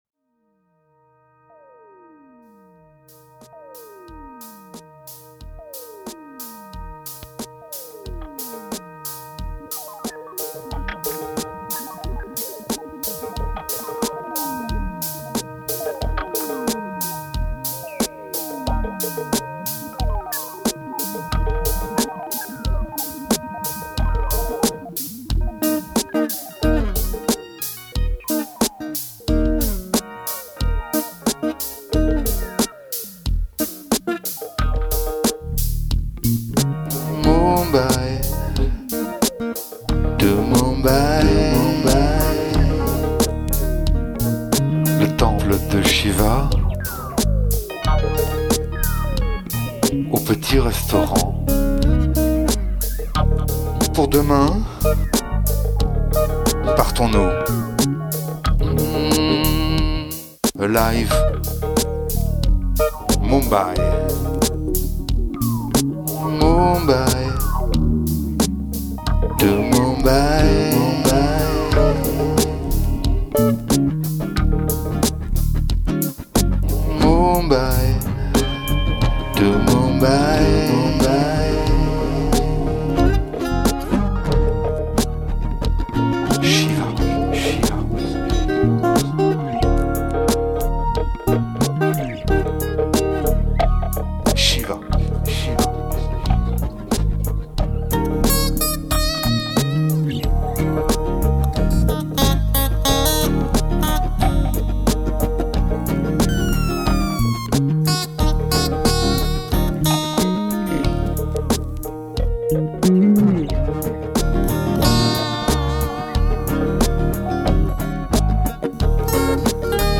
Mumbai - (Basse)